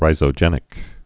(rīzō-jĕnĭk)